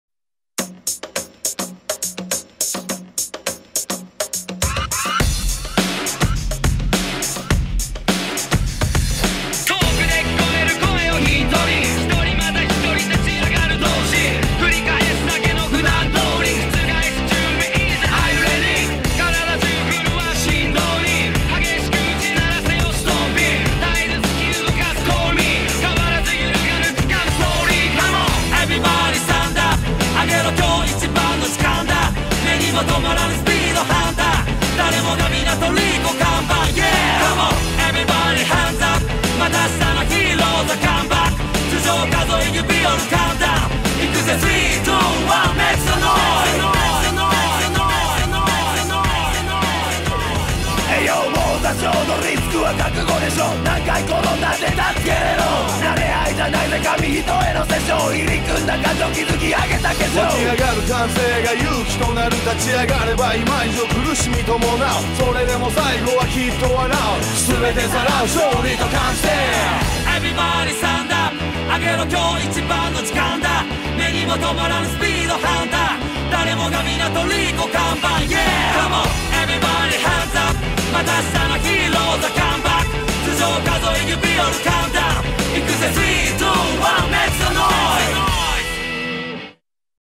شارة البداية